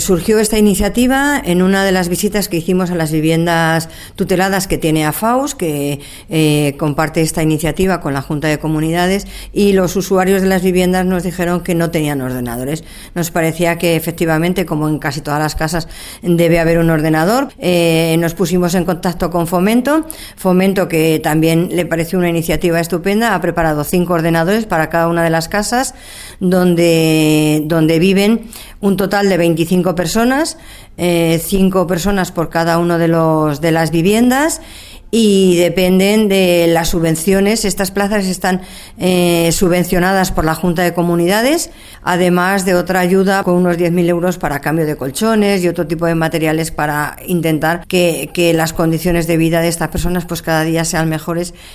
La directora provincial de Sanidad en Guadalajara, Margarita Gascueña, habla de los ordenadores facilitados por el Gobierno regional para las viviendas tuteladas de AFAUS y de otros programas de colaboración entre ambas entidades.